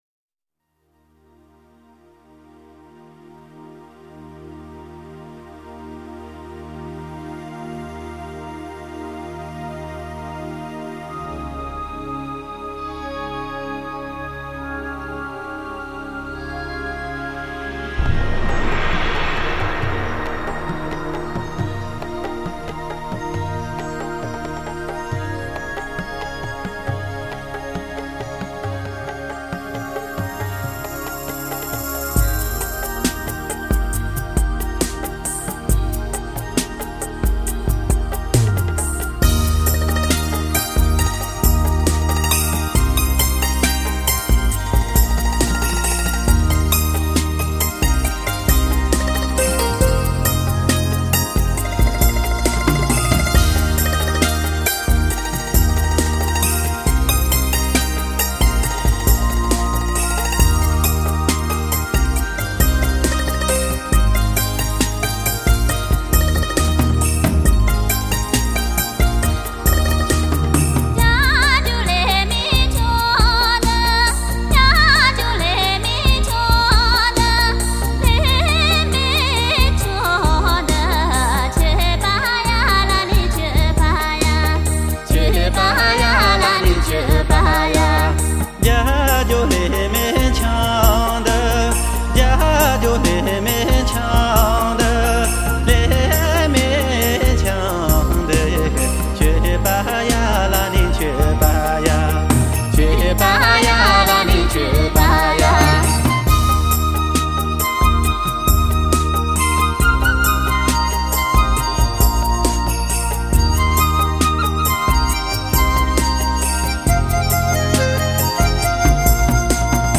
透明纯朴的人声、深情款款的嗓音为西部歌谣注入了无法抗拒的动人魅力。
没有一丝杂色的完美乡谣音乐配上顶级录音，更使得本辑音乐 充满了无可抵挡的诱人魅力与款款深情！